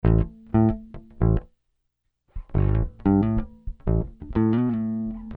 Hiphop music bass loop - 90bpm 74